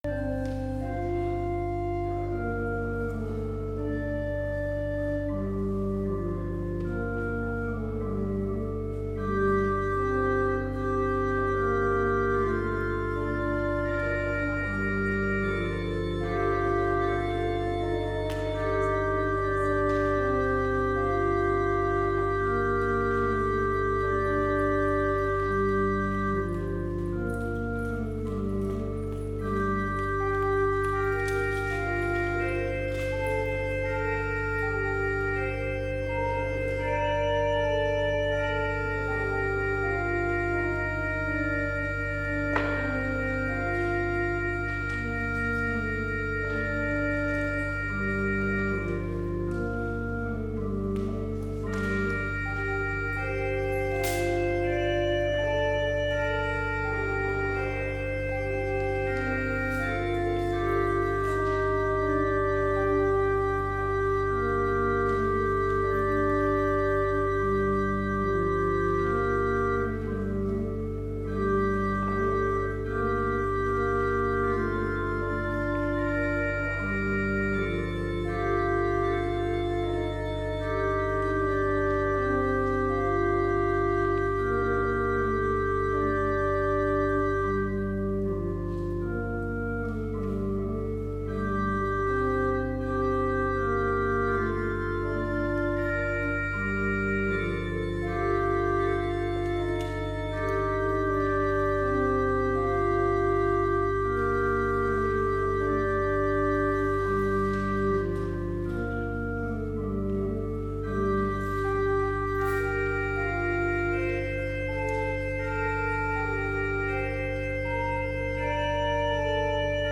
Complete service audio for Chapel - December 7, 2020
Order of Service Prelude Hymn 98 - Lo! He Comes With Clouds Descending